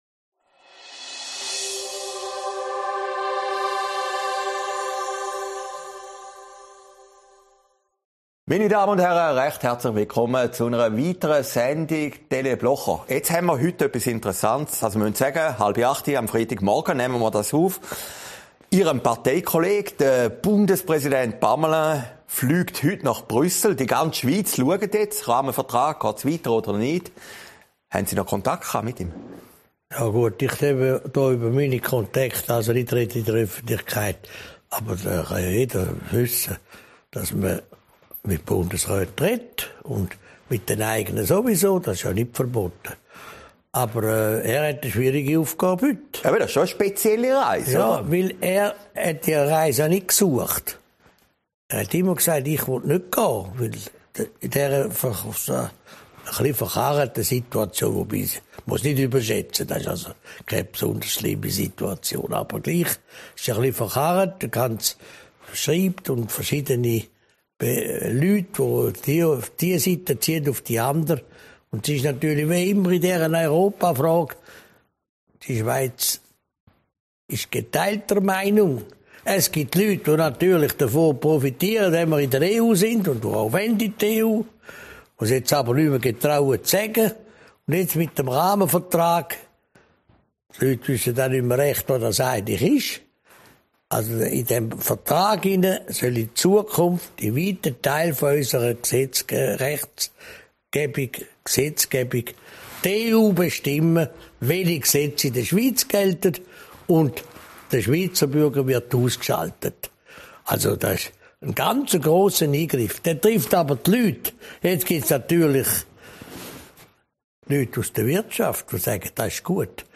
Aufgezeichnet in Herrliberg, 23. April 2021